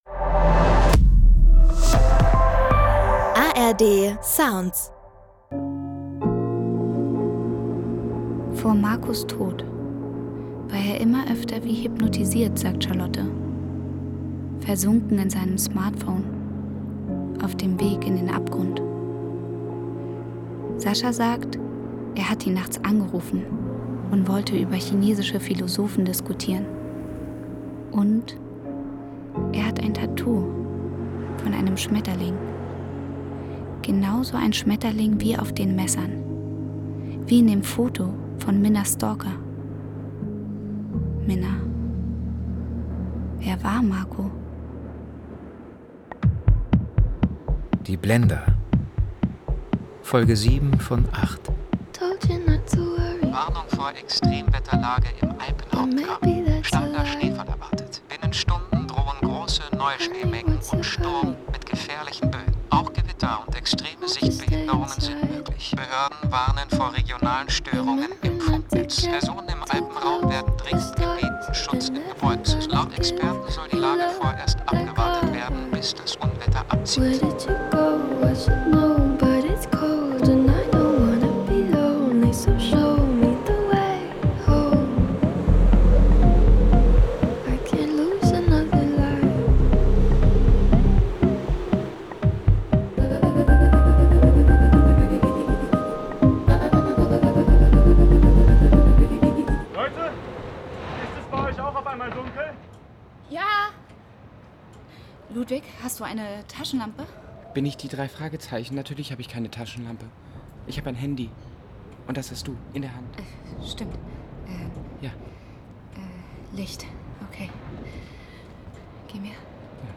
Die Blender – Crime-Hörspiel-Serie Podcast - Die Blender: Marco (7/8) – Jetzt spricht der Tote selbst | Free Listening on Podbean App